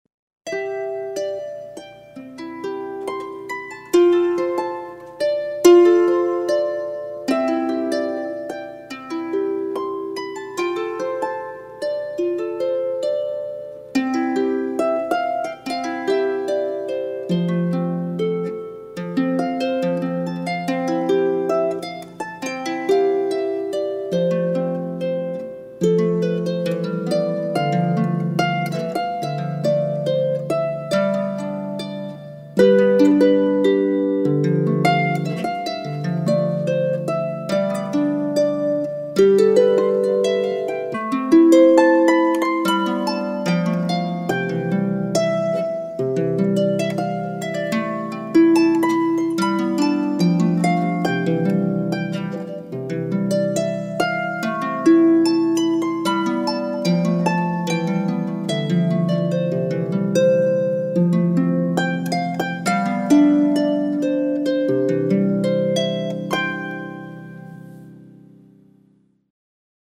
harpist
Click on a link below to listen to my Harp.